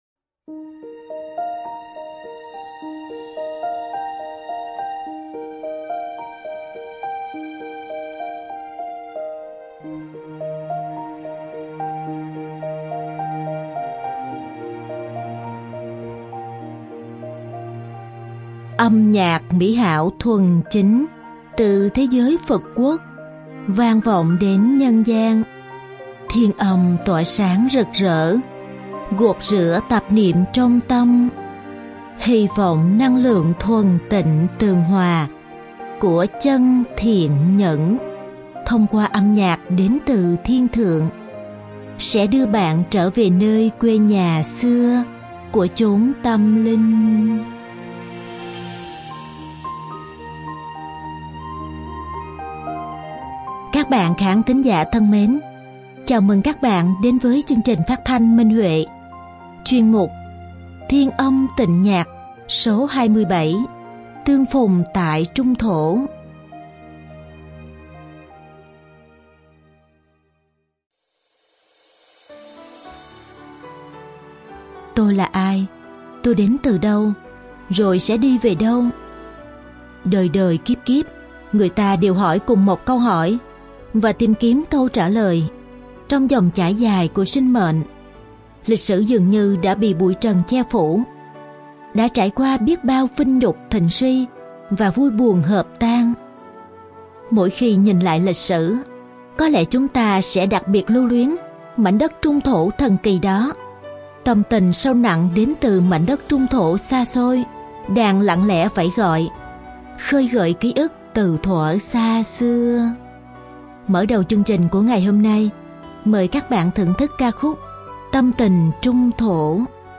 Đơn ca nữ